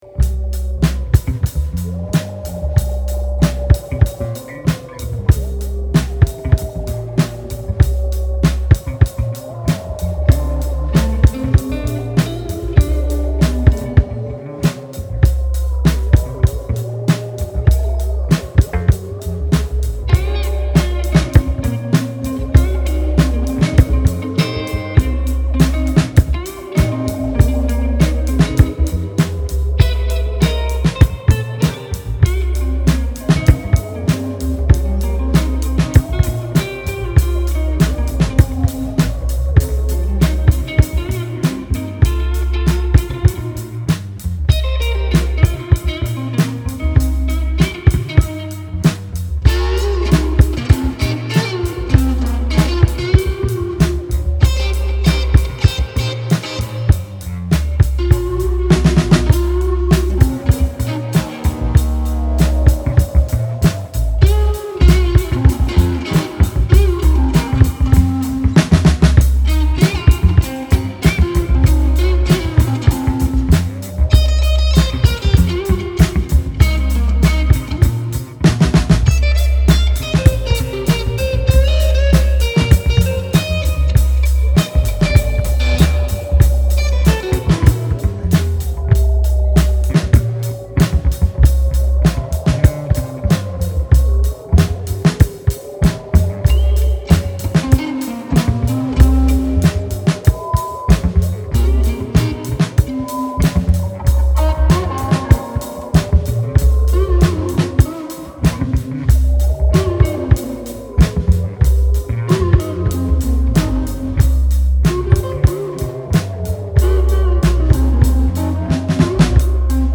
Here is a selection of music, sounds and songs recorded at Grrr Jamming Squeak, Saint Petersburg.
GRRR-Jamming-Squeak-Ethno-Mechanics-Jam-1.mp3